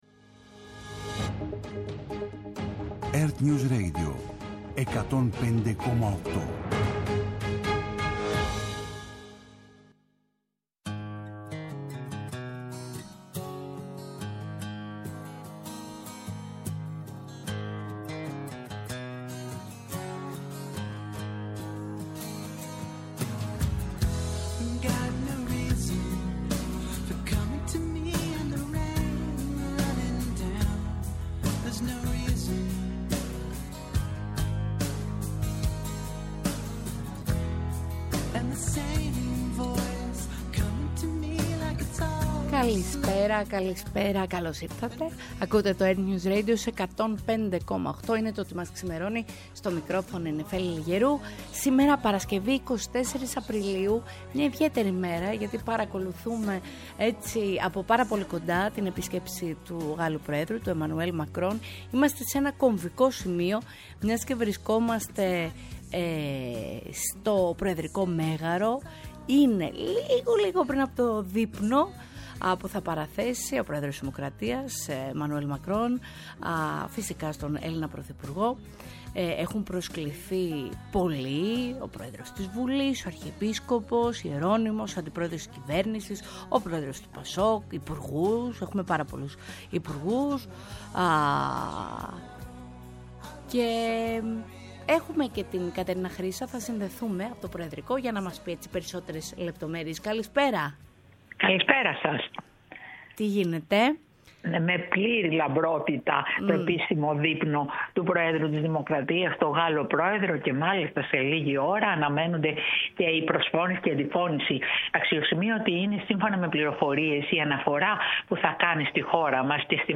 Σε απευθείας σύνδεση με το Προεδρικό Μέγαρο παρακολουθούμε τις προσφωνήσεις του προέδρου της Δημοκρατίας Κων/ντίνου .Τασούλα και του προέδρου Εμανουέλ Μακρόν στο επίσημο δείπνο προς τιμήν του Γάλλου προέδρου.